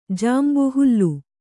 ♪ jāmbu hullu